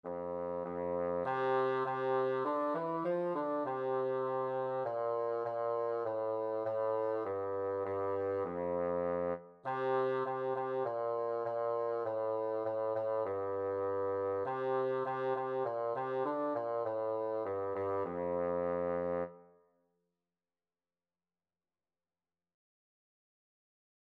Traditional Trad. Baa, Baa Black Sheep Bassoon version
4/4 (View more 4/4 Music)
F3-F4
F major (Sounding Pitch) (View more F major Music for Bassoon )
Moderato
Bassoon  (View more Beginners Bassoon Music)
Traditional (View more Traditional Bassoon Music)